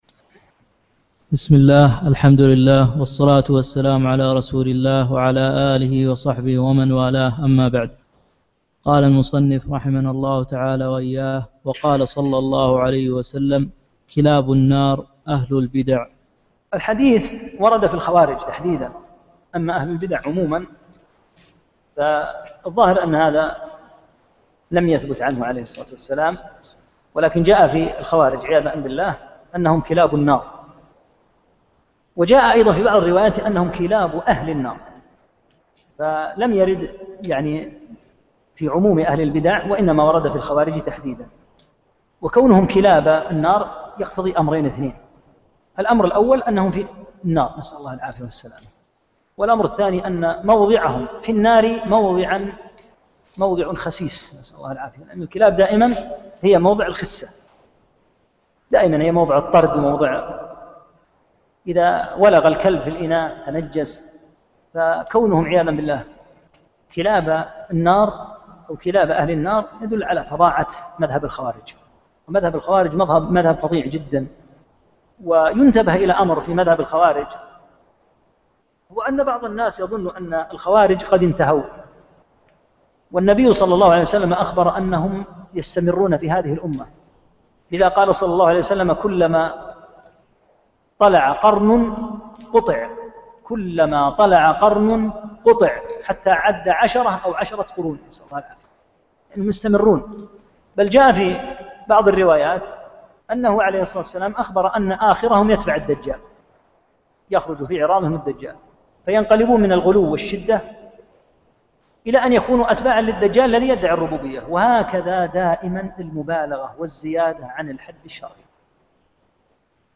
6 - الدرس السادس